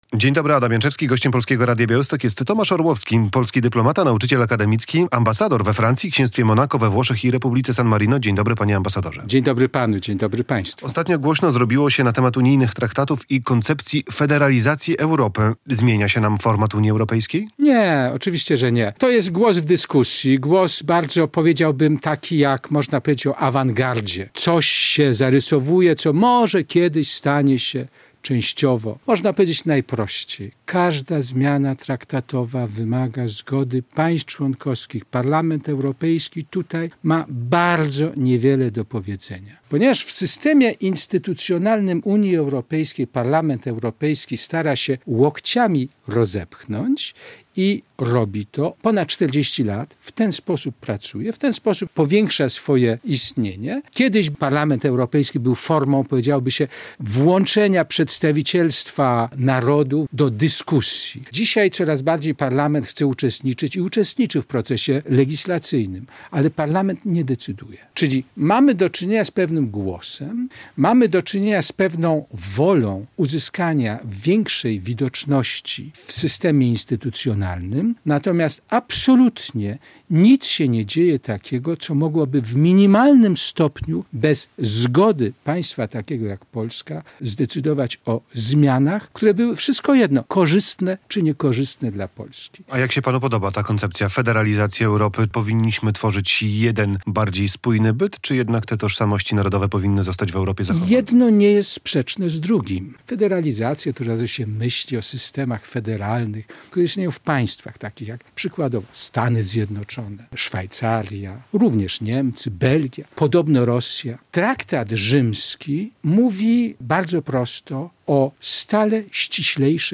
"Im donośniejszy głos Polski, tym większe nasze możliwości wpływu na to, żeby Unia zmieniała się w kierunku odpowiadającym naszym aspiracjom i potrzebom" - mówił w Polskim Radiu Białystok polski dyplomata Tomasz Orłowski.